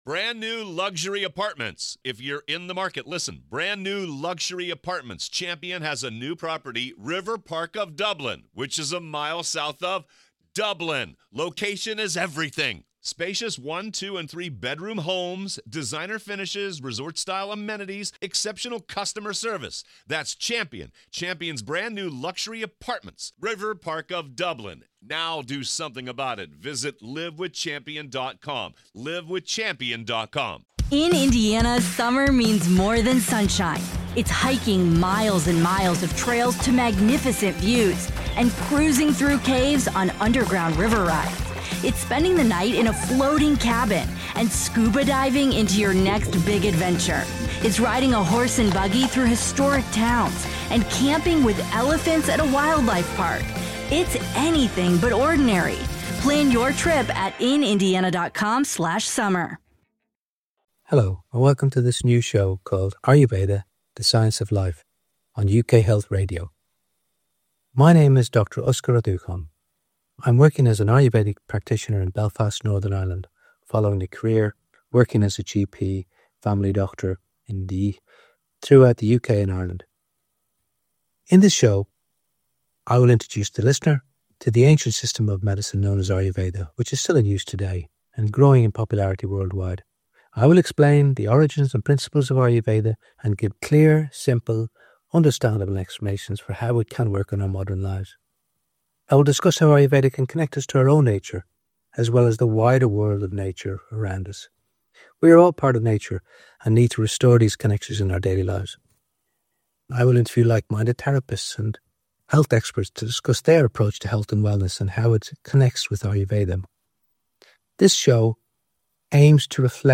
I'll interview therapists and health experts who share a holistic view of wellness.